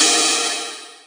Index of /90_sSampleCDs/Club_Techno/Percussion/Cymbal
Ride_05.wav